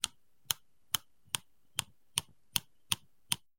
На этой странице собраны звуки микроскопа — от щелчков регулировки до фонового гула при работе.
Звуки микроскопа: Крутилка старого микроскопа